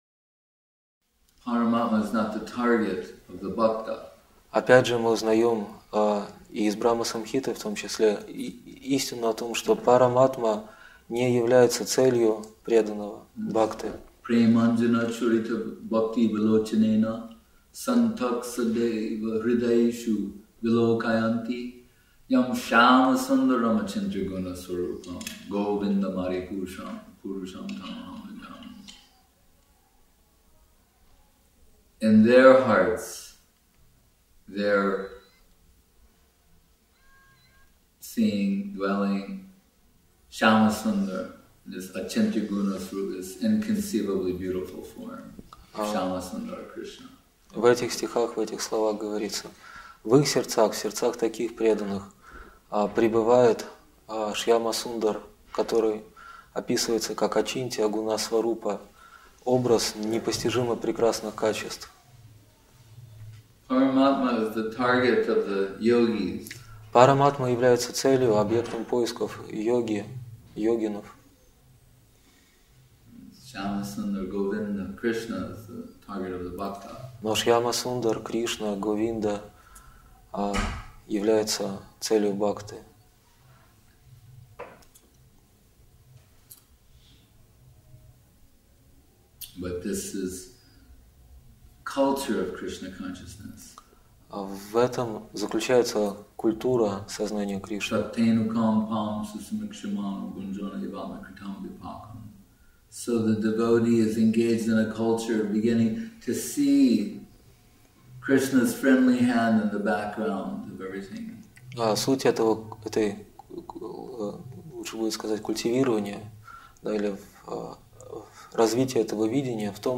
Место: Культурный центр «Шри Чайтанья Сарасвати» Москва